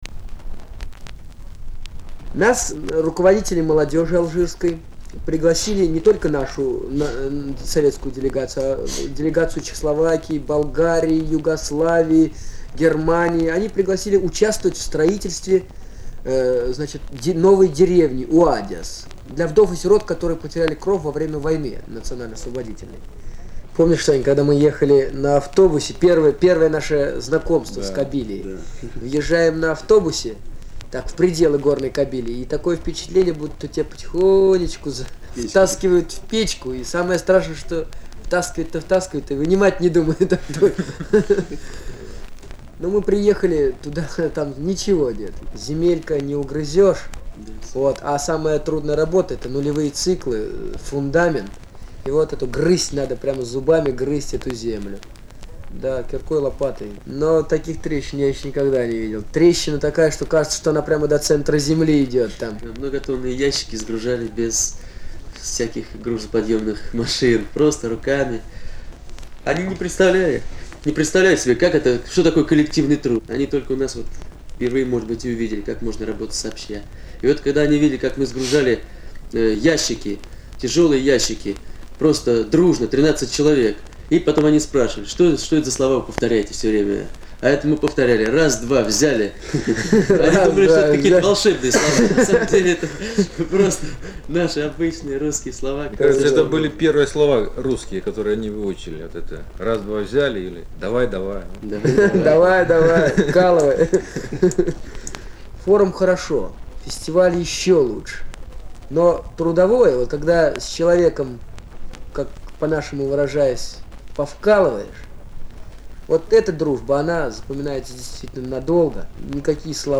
Звуковая страница 3  -  В деревне рядом с Сахарой - наши ребята. Рассказы советских строителей в Алжире.